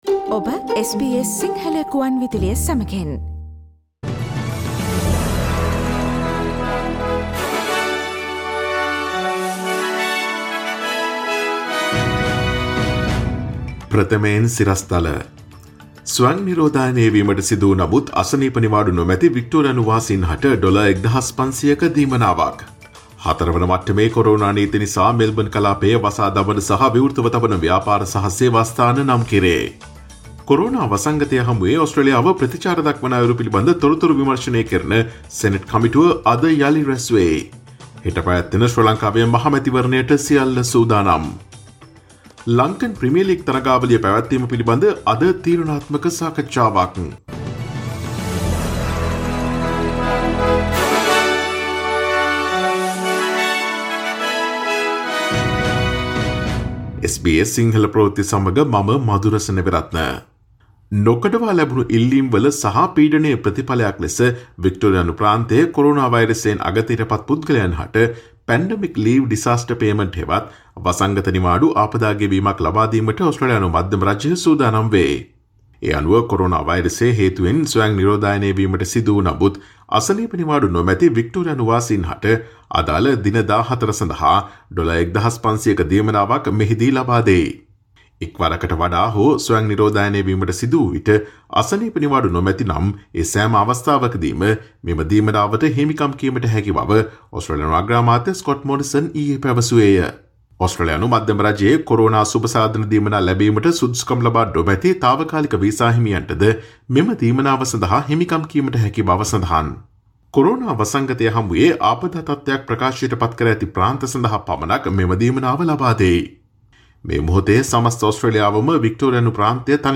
Daily News bulletin of SBS Sinhala Service: Tuesday 04 August 2020